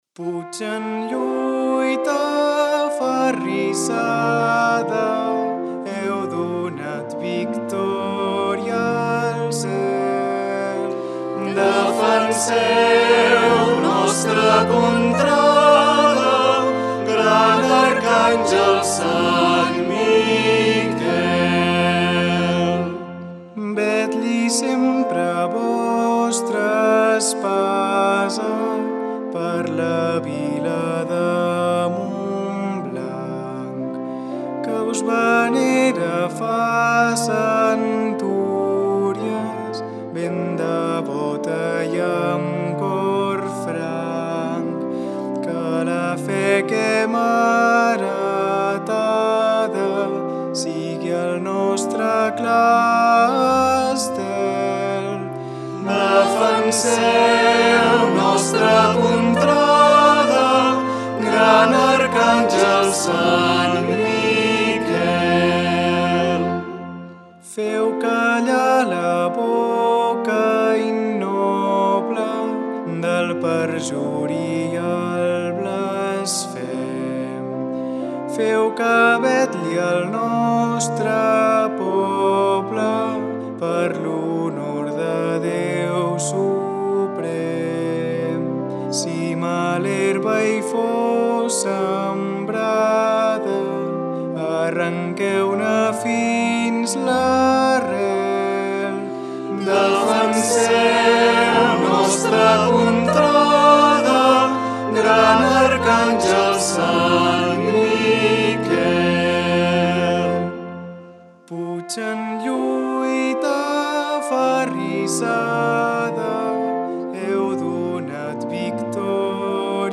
A continuació trobareu diverses músiques populars religioses de Montblanc digitalitzades amb motiu de l’Any de la Mare de Déu (setembre 2021- setembre 2022):